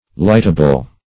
Lightable \Light"a*ble\ (l[imac]t"[.a]*b'l), a. Such as can be lighted.